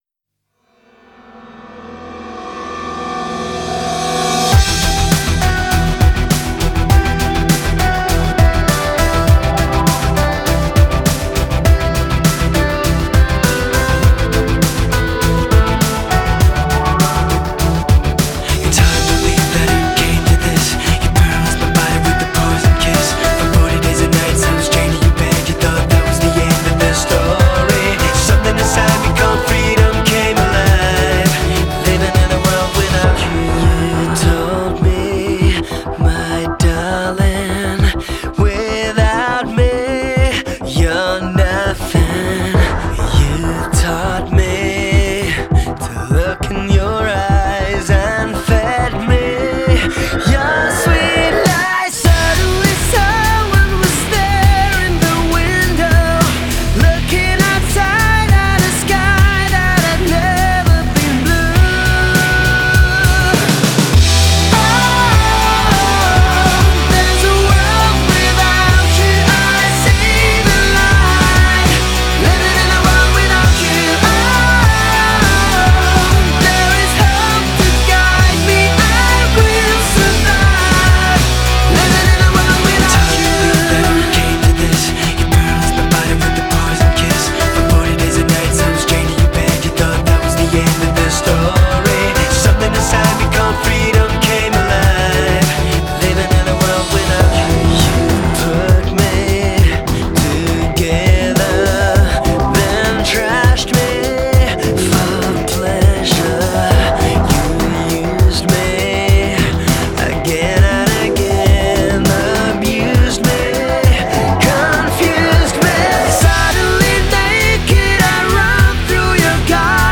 Список файлов рубрики Rock